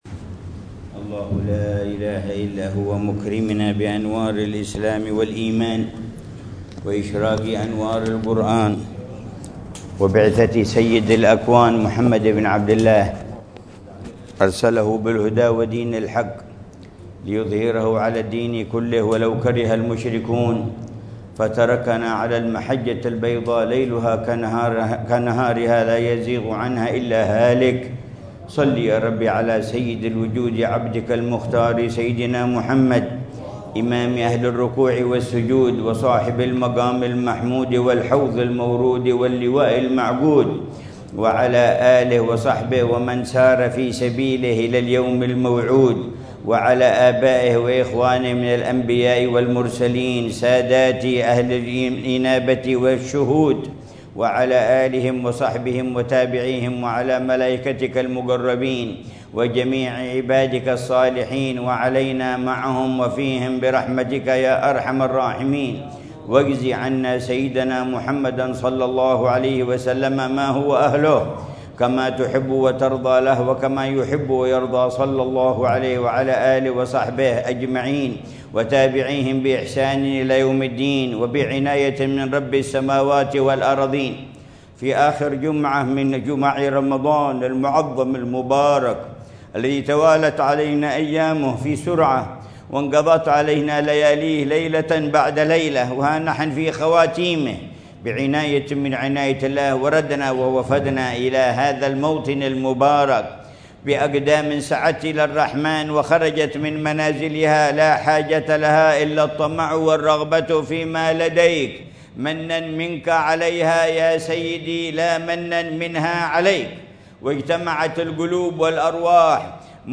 مذاكرة العلامة الحبيب عمر بن محمد بن حفيظ في مسجد الشيخ أبي بكر بن سالم في منطقة عينات، وادي حضرموت، ظهر الجمعة 28 رمضان 1446هـ بعنوان: دروس رمضان وأثرها في الفكر والسلوك